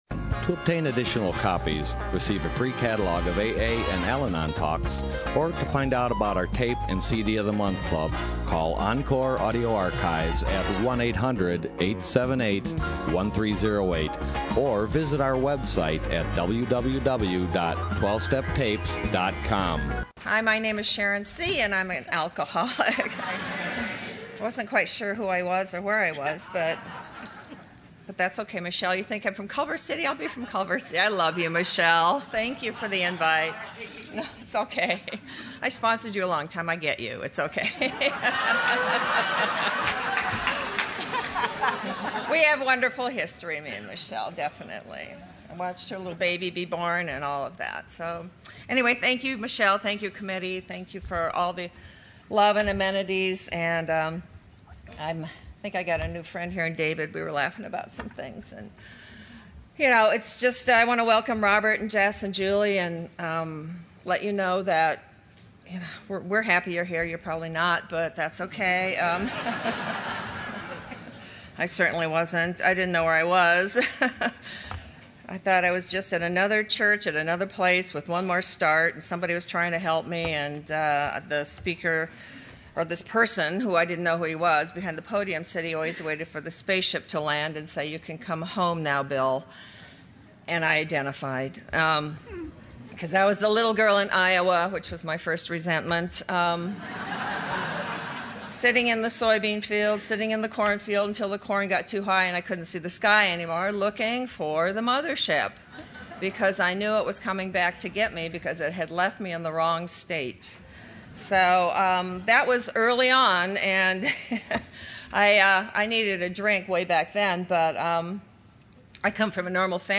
Orange County AA Convention 2013